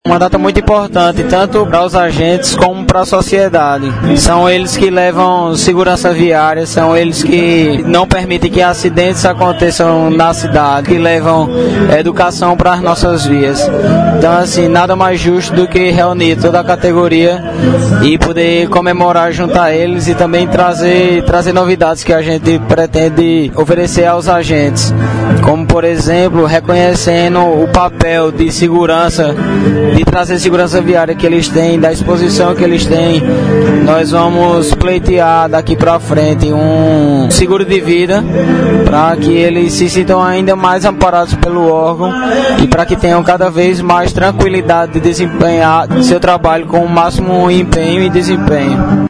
A confraternização seguiu com homenagens e discursos do superintendente, Aldo Xavier, e de agentes de trânsito e foi oferecido um jantar para todos os que lá se fizeram presentes.
Superintendente da STTRANS, Aldo Xavier